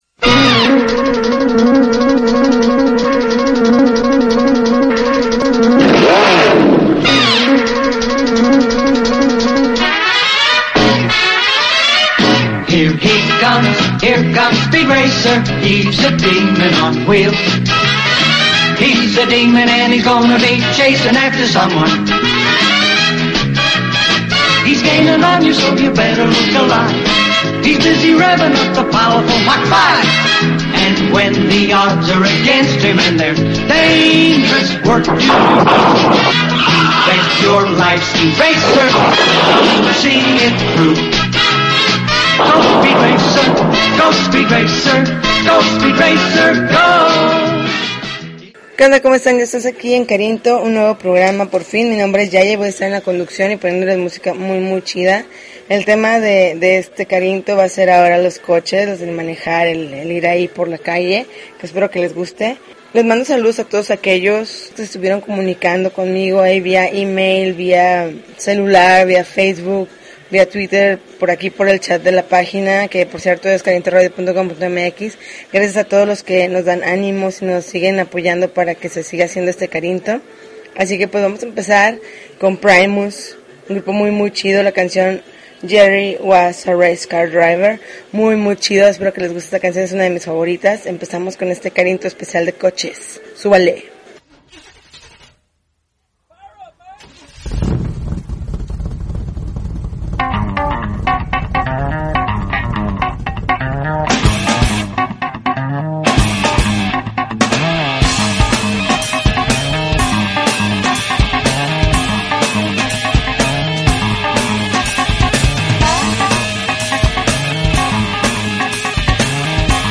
June 3, 2013Podcast, Punk Rock Alternativo